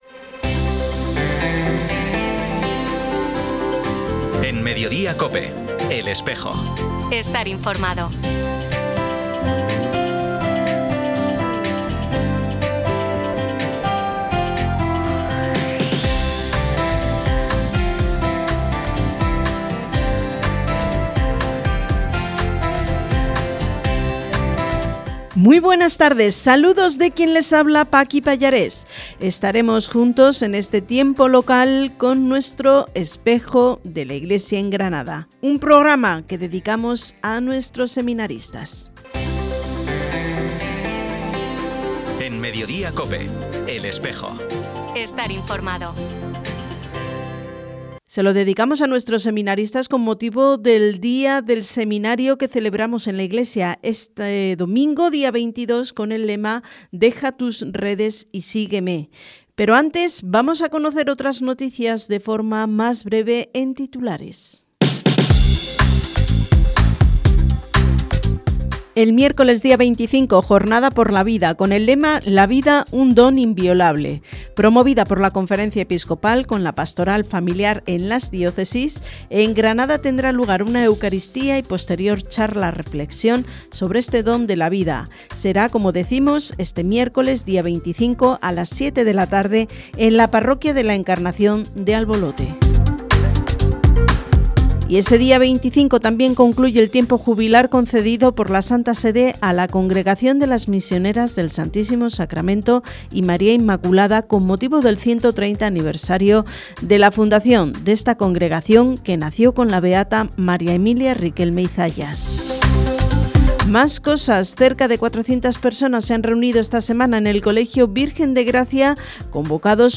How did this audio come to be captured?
Programa emitido en COPE Granada y COPE Motril el 20 de marzo de 2026.